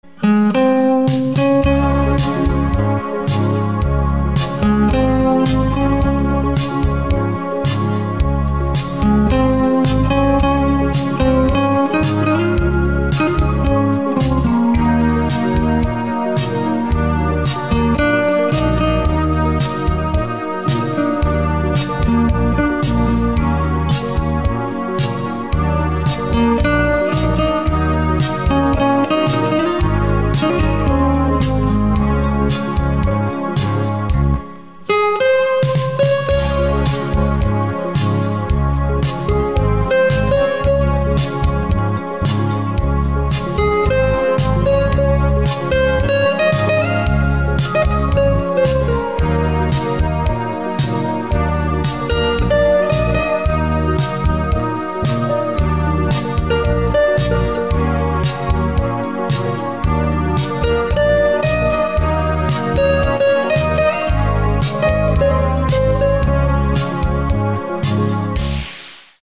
инструментальные
спокойные